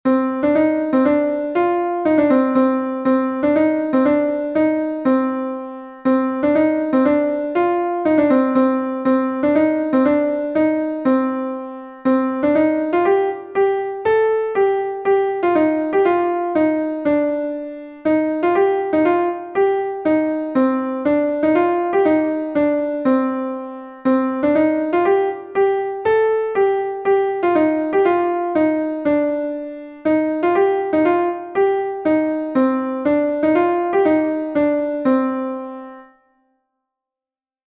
Ton Bale Plouie est un Bale de Bretagne